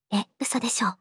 voicevox-voice-corpus
voicevox-voice-corpus / ita-corpus /四国めたん_ヒソヒソ /EMOTION100_001.wav